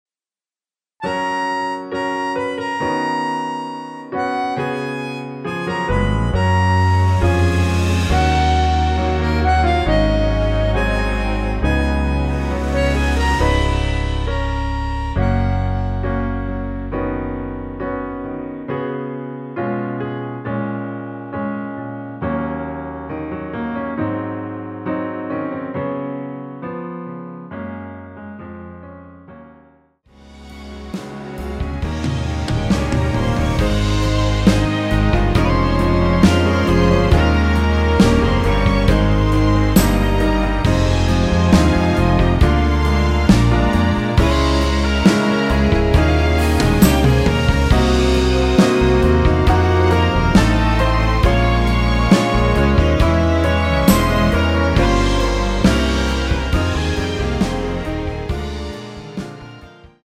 F#
◈ 곡명 옆 (-1)은 반음 내림, (+1)은 반음 올림 입니다.
앞부분30초, 뒷부분30초씩 편집해서 올려 드리고 있습니다.